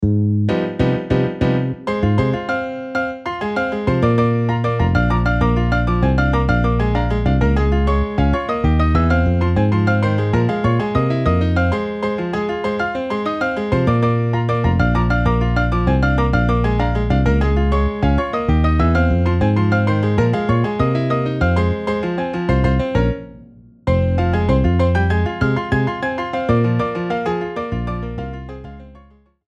Saxo Alto, 2X Trompetas, 2X Trombones, Piano, Bajo